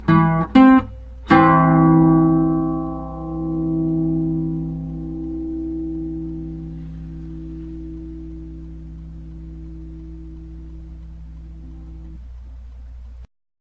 • The Bajo Sexto is a twelve-string guitar.
Play(P)just the last two courses of strings.
C chord